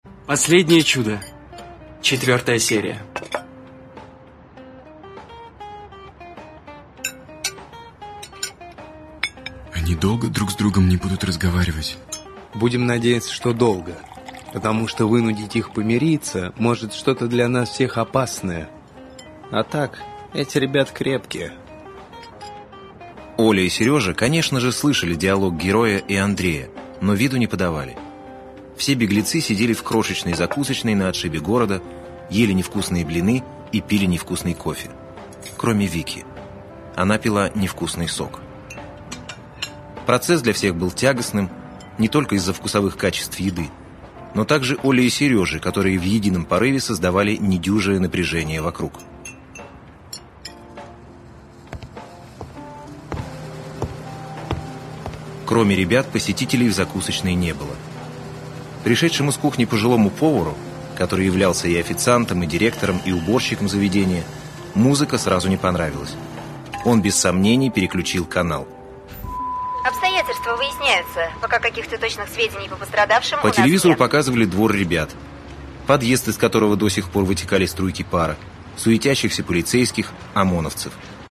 Аудиокнига Последнее чудо. Эпизод 4: Чрезвычайное положение | Библиотека аудиокниг